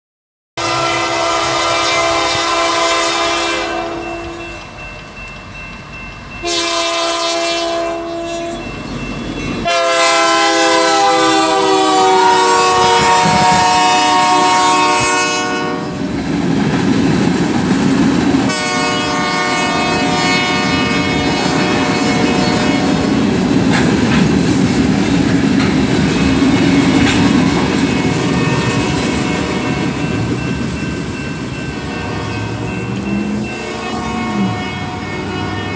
…A train passing while I was sitting at a railroad crossing.